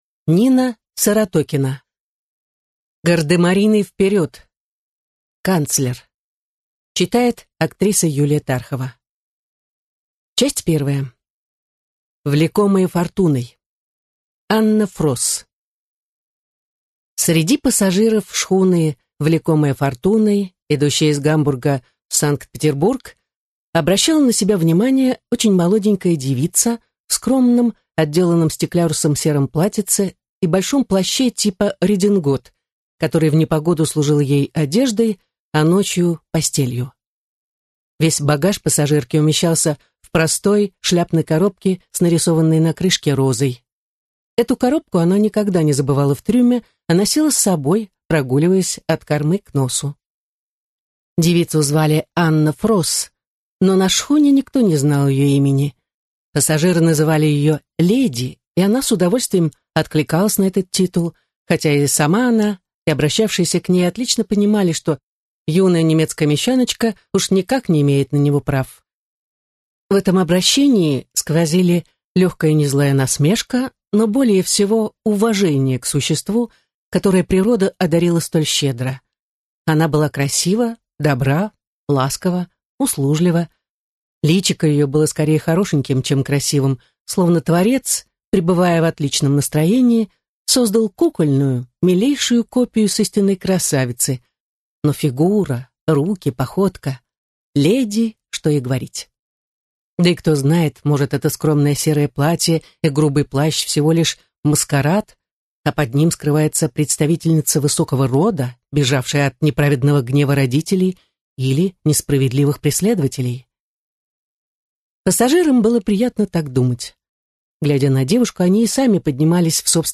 Аудиокнига Гардемарины, вперед! Канцлер_3 | Библиотека аудиокниг